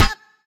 hero_stumble.ogg